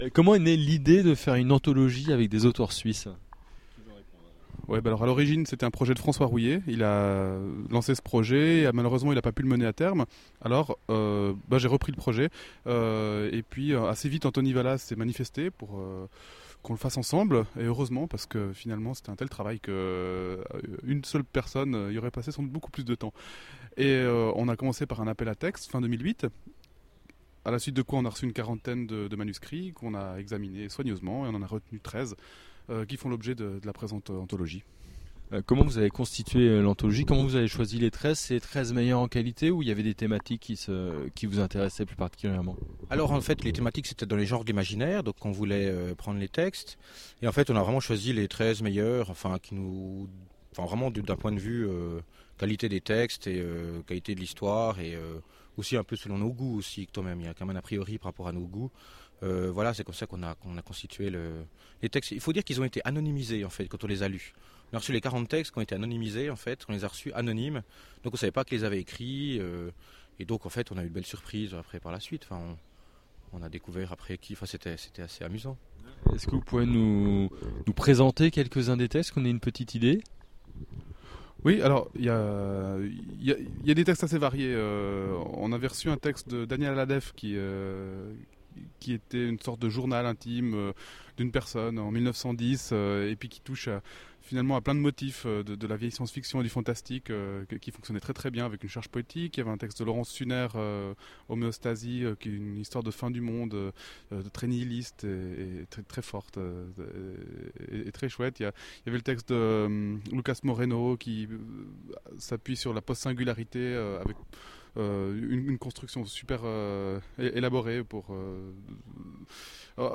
Voici une interview audio réalisée aux Imaginales